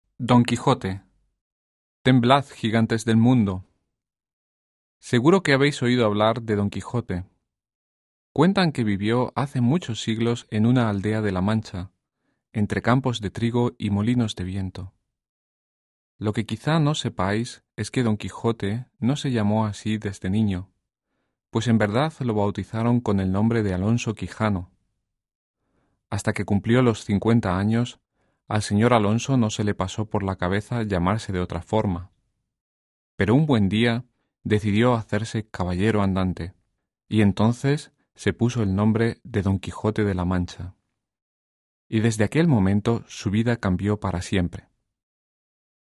Interaktives Hörbuch Spanisch